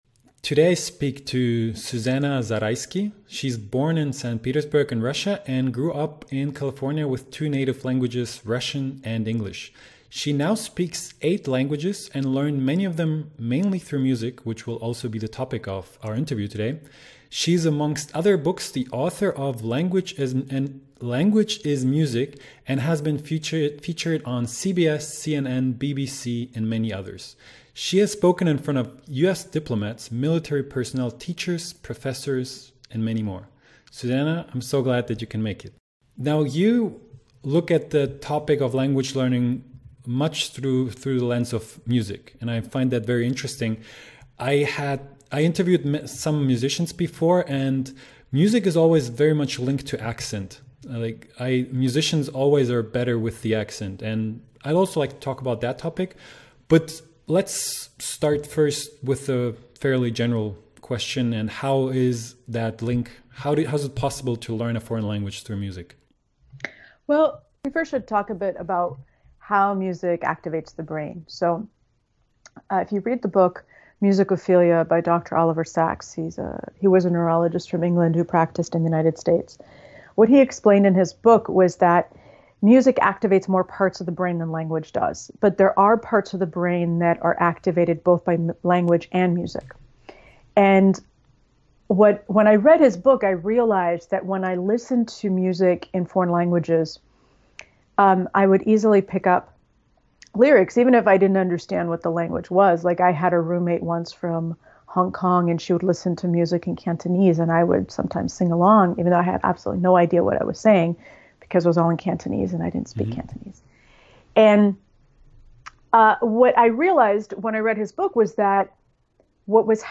Wie lernst Du eine Fremdsprache mit Musik? Interview